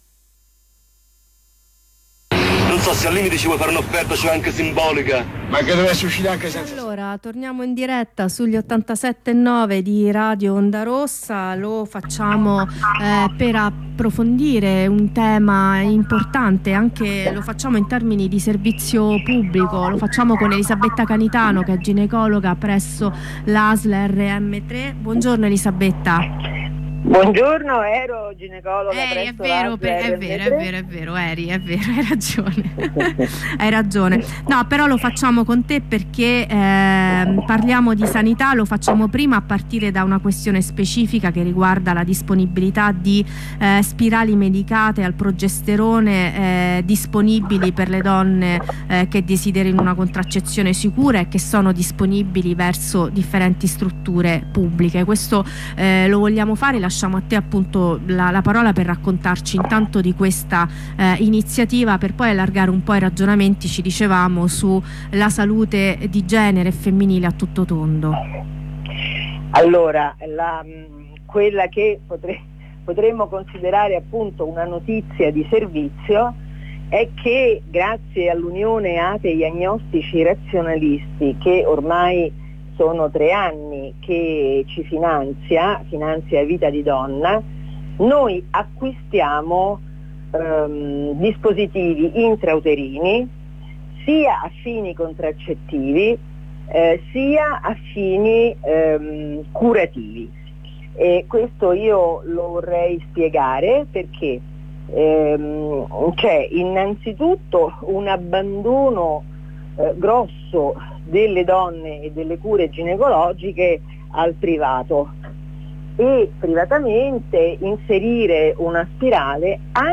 Corrispondenza con un'avvocata del Legal Team, dopo la mattinata di identificazioni